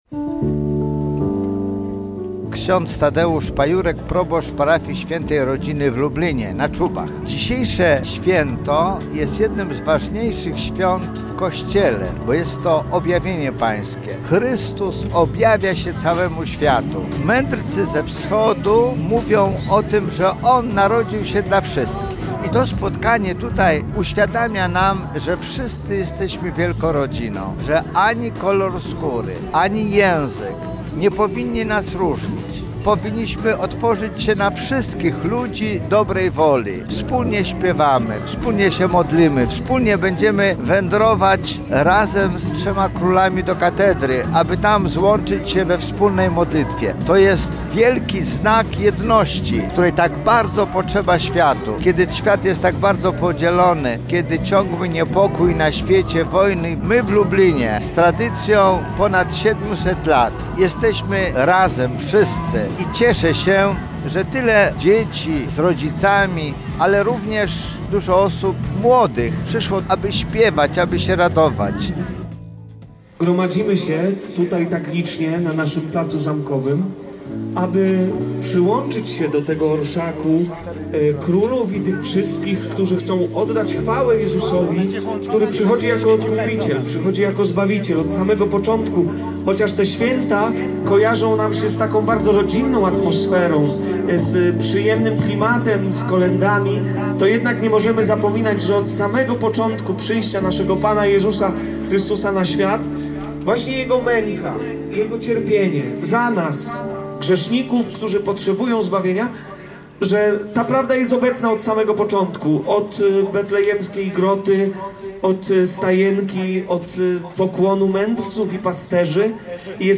Orszak Trzech Króli przyciągnął tłumy lublinian.